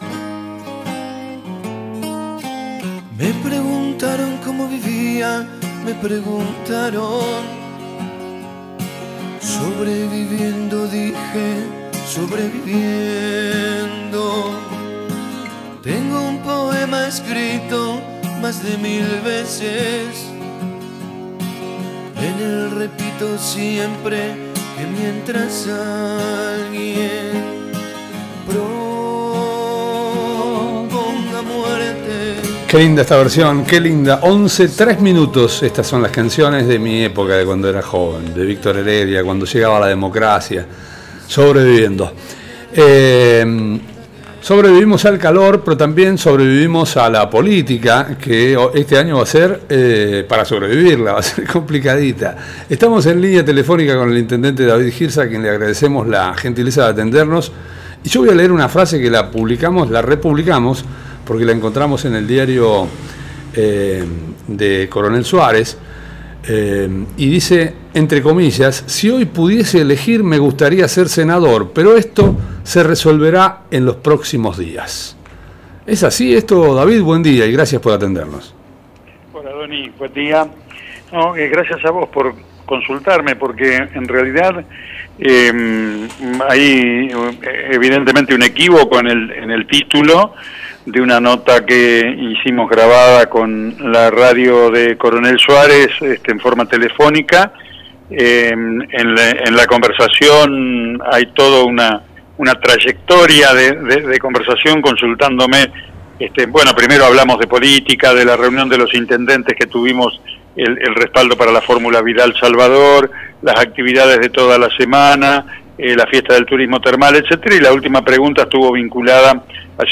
El Intendente Hirtz rectificó en una entrevista realizada por La Nueva Radio Suarez, el título de la nota que daba cuenta de sus preferencias políticas.